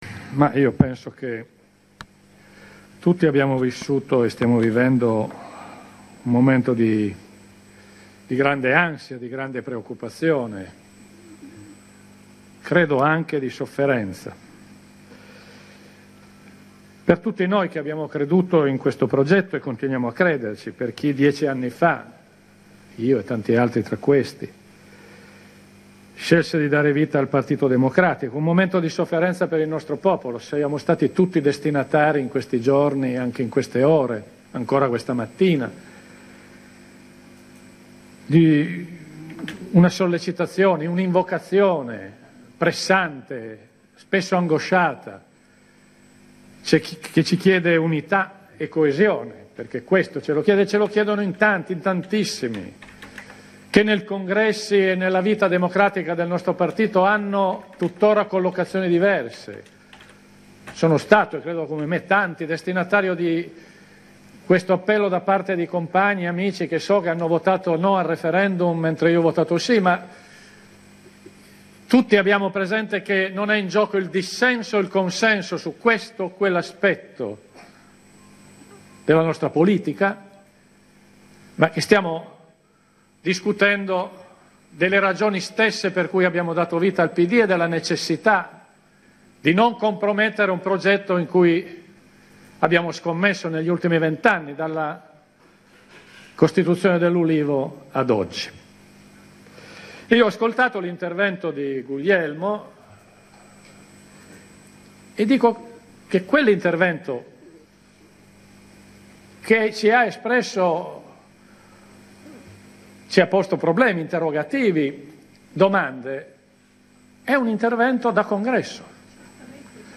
L’intervento di PIERO FASSINO all’Assemblea nazionale del Partito Democratico, 19 febbraio 2017, AUDIO di 16 minuti